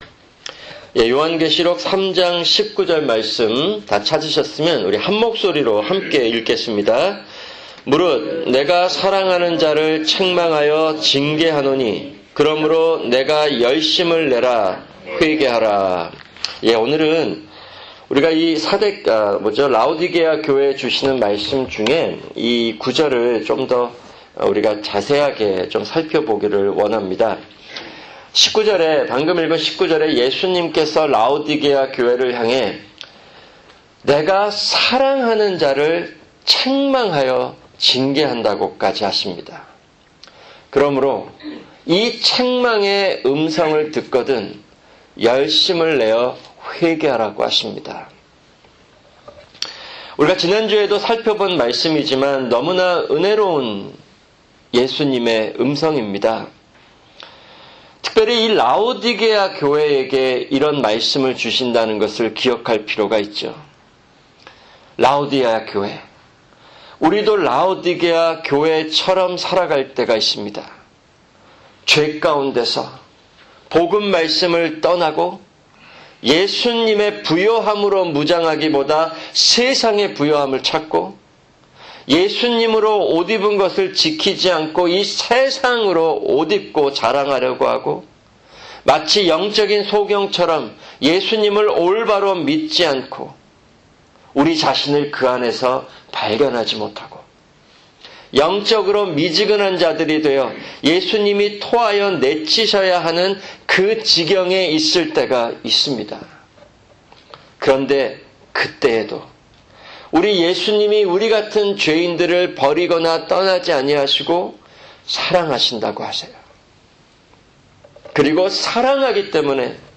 [금요 성경공부] 일곱 교회(27) 계3:14-22(6)